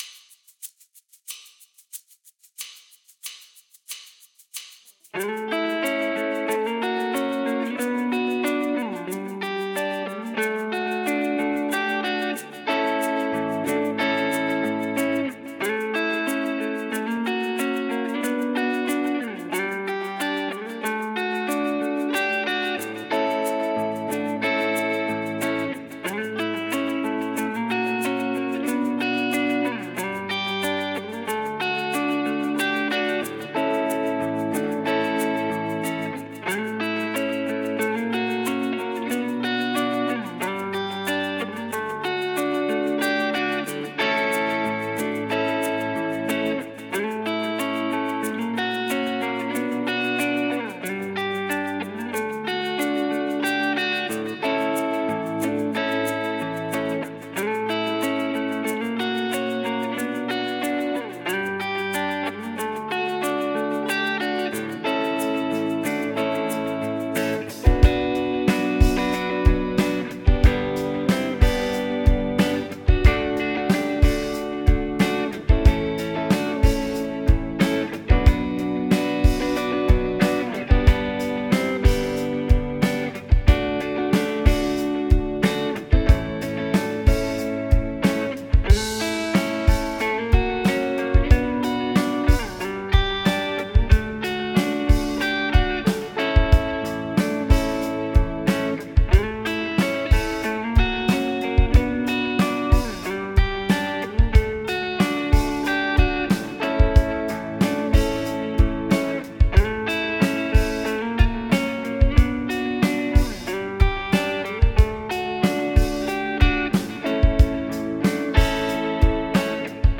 BPM : 92
Tuning : E
Without vocals